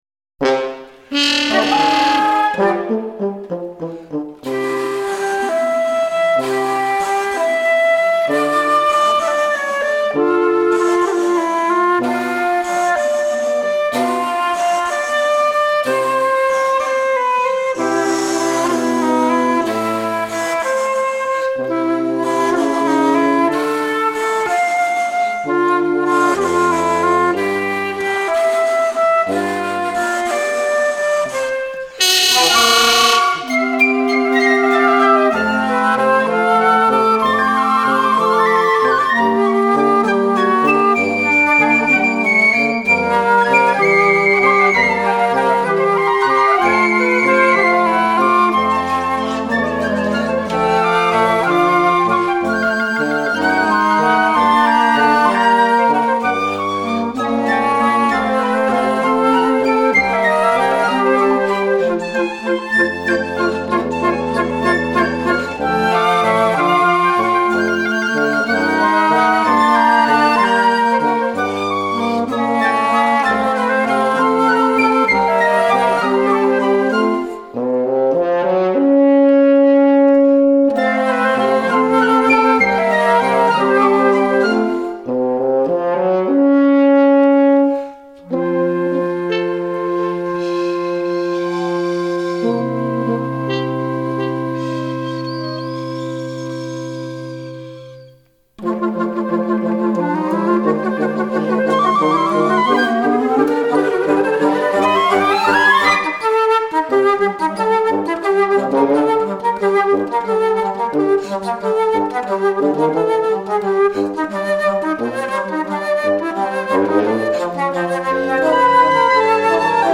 Invitación al Vals para vientos